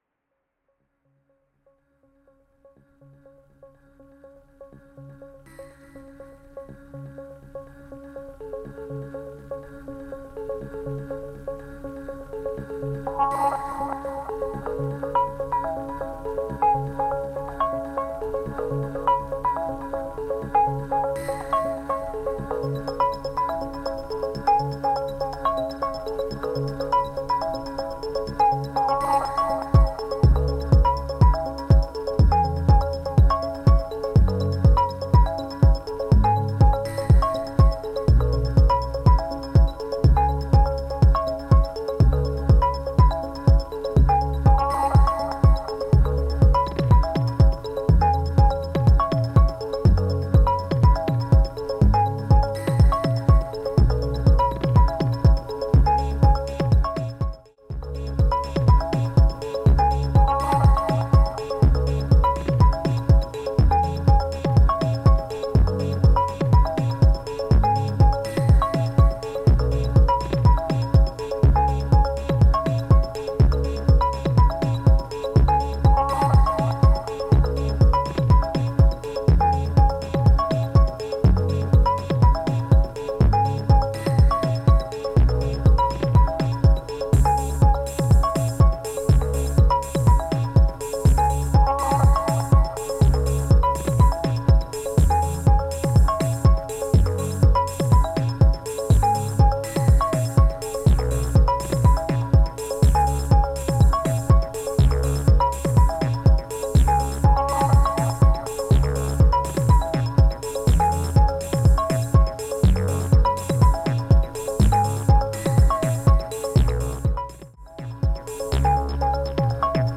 T-1, ARmk2, Rev2, P12 and a borrowed TB03.